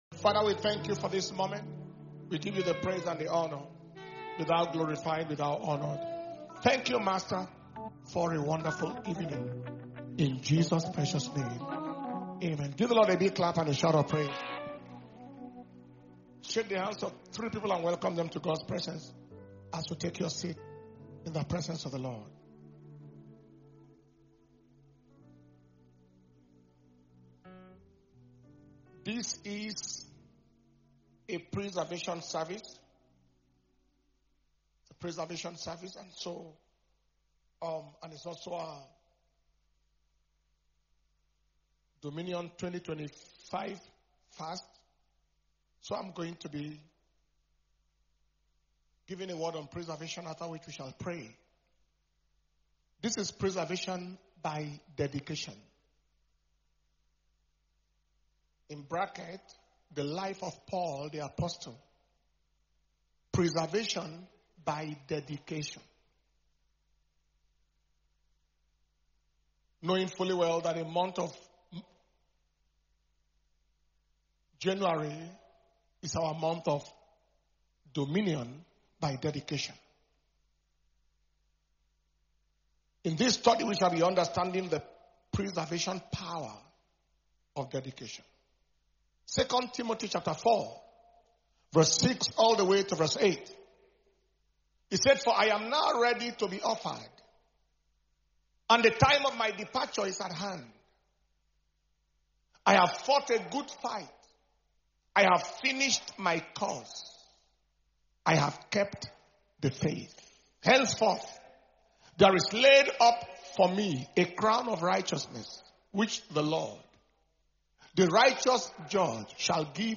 January 2025 Preservation And Power Communion Service